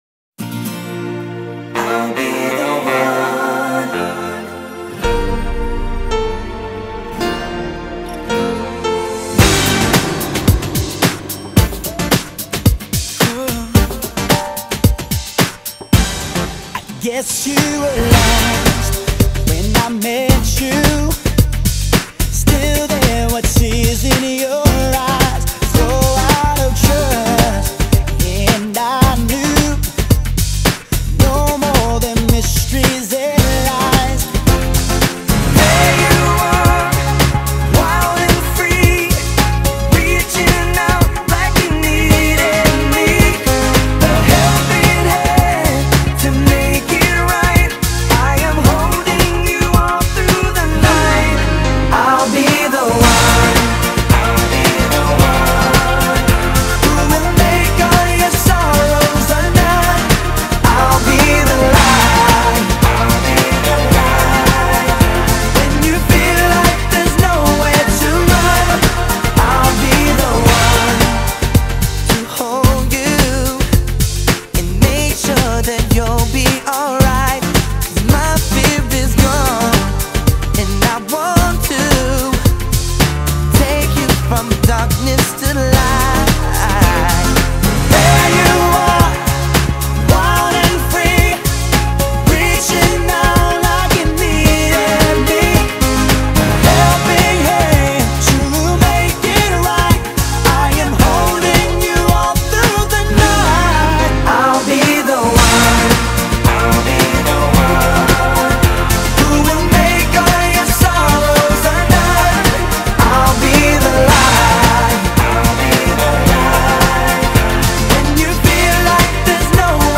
наполнена искренними эмоциями и надеждой